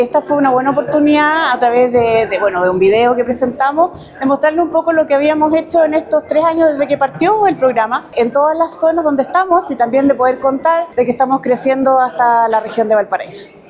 Con la presencia de representantes del sector público y privado, instituciones académicas y directivos de Servicios Sanitarios Rurales (SSR), se llevó a cabo el primer Seminario Regional “Servicios Sanitarios Rurales de O’Higgins: Desafíos y Oportunidades hacia el 2036” en la sede de Inacap Rancagua.